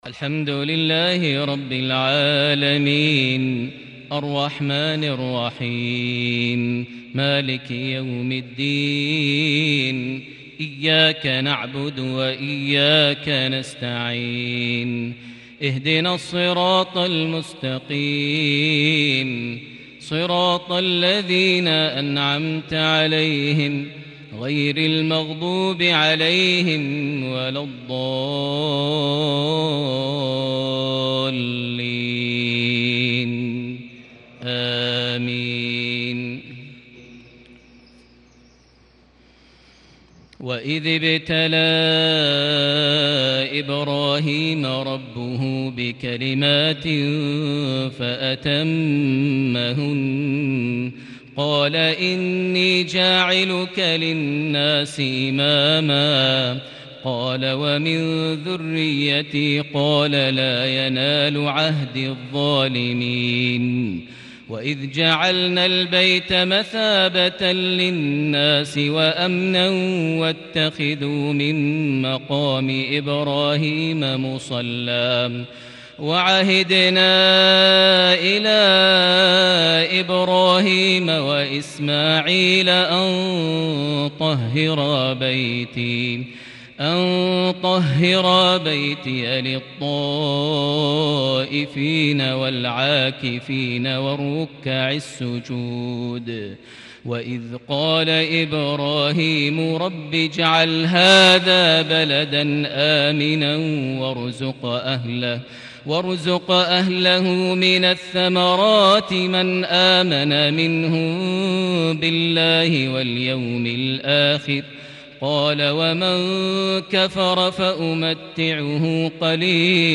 صلاة الفجر ٢ محرم ١٤٤١هـ سورة البقرة ١٢٤-١٣٤ > 1441 هـ > الفروض - تلاوات ماهر المعيقلي